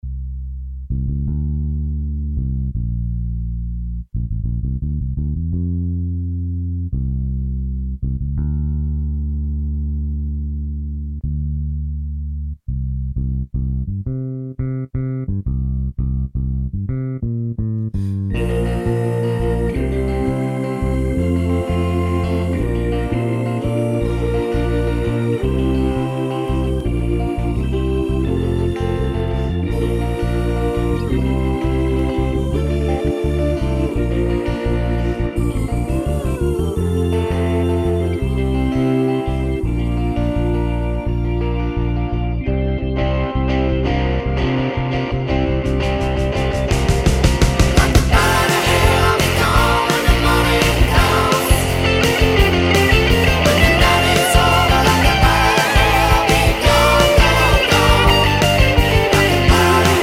Minus Piano With Count Rock 8:10 Buy £1.50